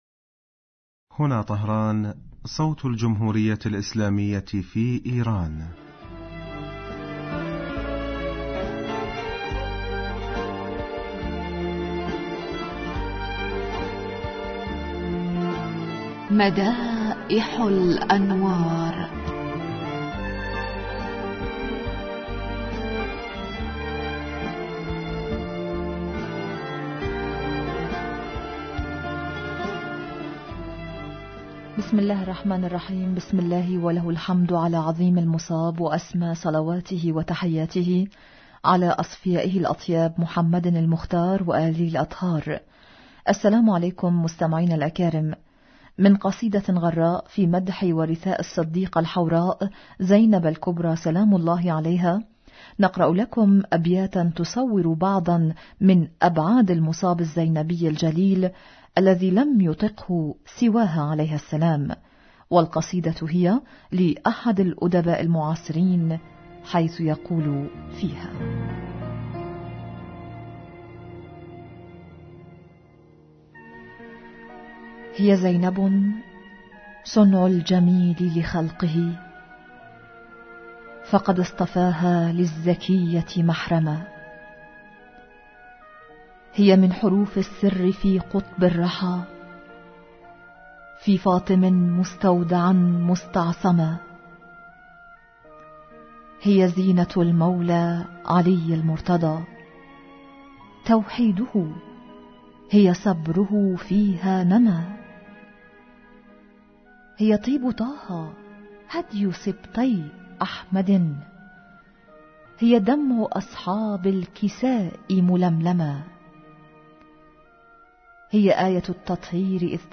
السلام عليكم مستمعينا الأكارم، من قصيدة غراء في مدح ورثاء الصديقة الحوراء زينب الكبرى عليها السلام نقرأ لكم أيها الأعزاء أبياتاً تصور بعضاً من أبعاد المصاب الزينبي الجليل الذي لم ليطيقه سواها عليها السلام، والقصيدة هي لأحد الأدباء المعاصرين يقول فيها: